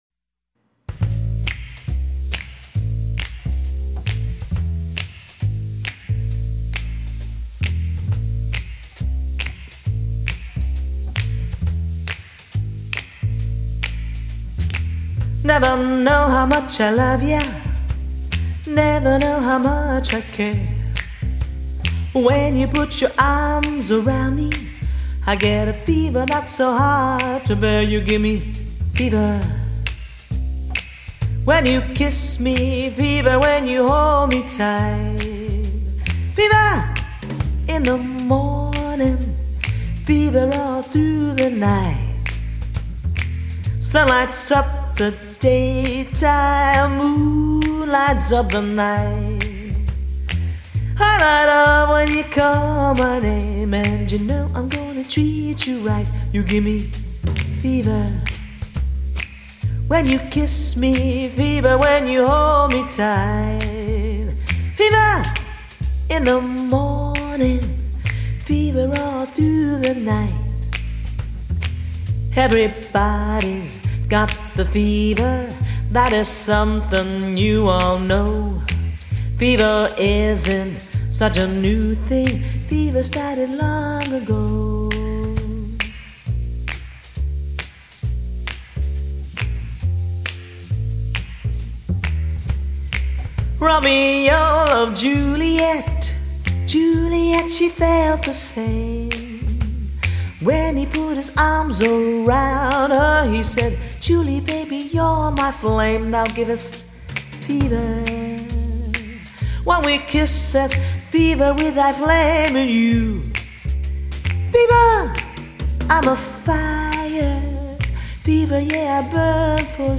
American Jazz Standard songs